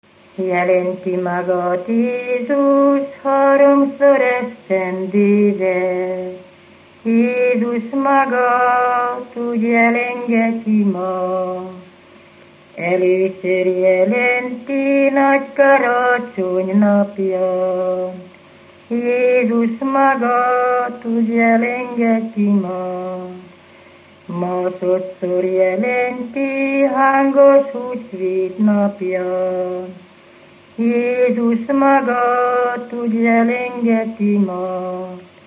Felföld - Nyitra vm. - Ghymes
Műfaj: Szentiváni ének
Stílus: 7. Régies kisambitusú dallamok
Kadencia: 5 (2) 1